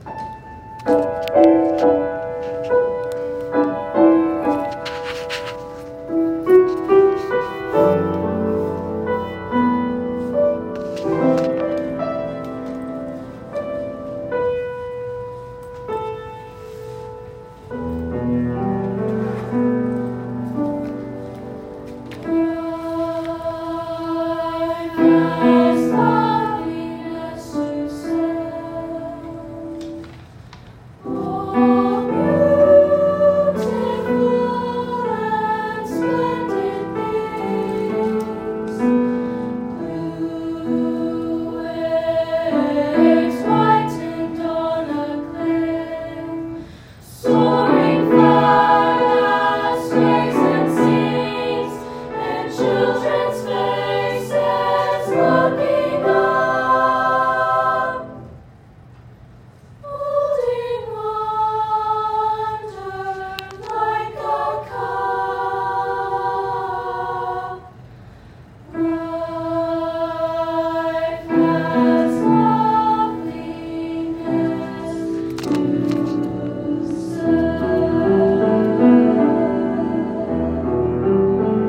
a piece they have practiced for the Solo and Ensemble competition this weekend, which acts as a student-conducted piece
ensemble